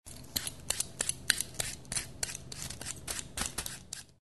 На этой странице собраны натуральные аудиоэффекты, связанные с огурцами: от хруста свежего овоща до звуков его выращивания.
Чистим огуречную кожуру овощечисткой